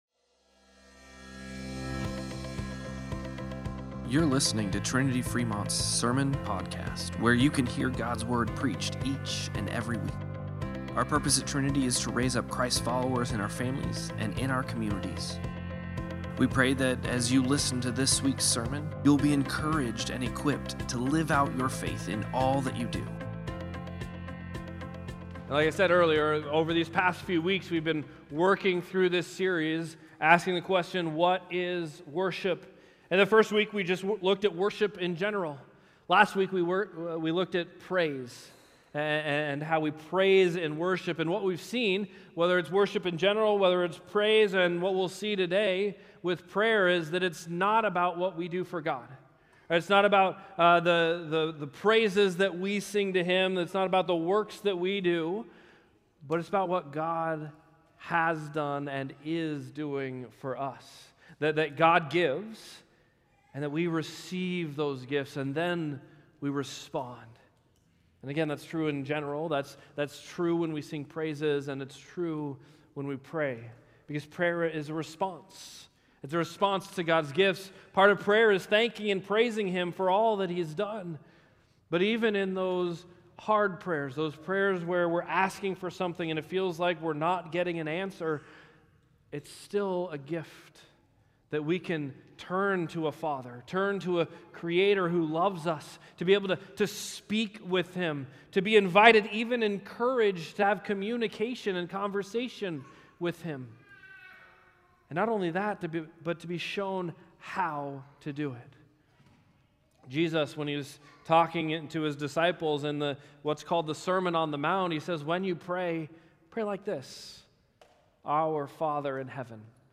8-10-Sermon-Podcast.mp3